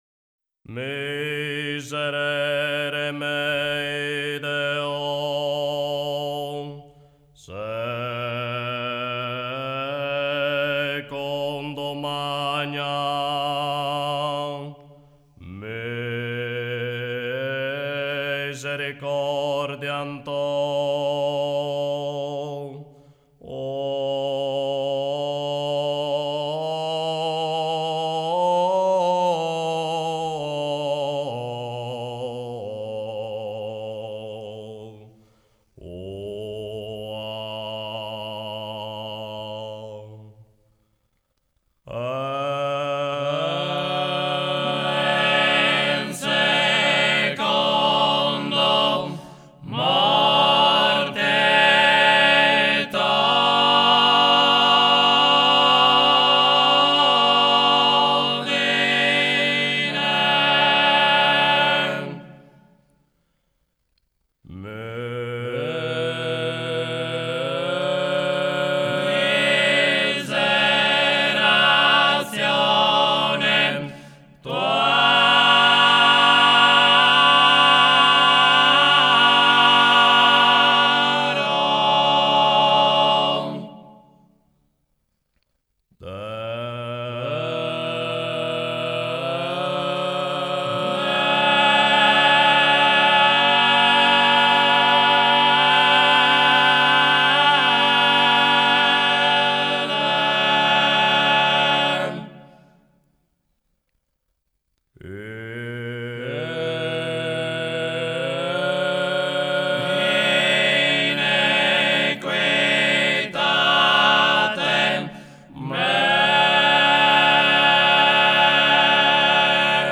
Il testo, in latino, è tratto dal Libro dei Salmi, mentre la melodia, introdotta dal bassu, viene tramandata per tradizione orale.
perform this Miserere.The text, in Latin, is taken from the Book of Psalms, while the melody, introduced by the bassu, has been passed down by oral tradition.